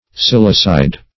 Silicide \Sil"i*cide\, n. (Chem.)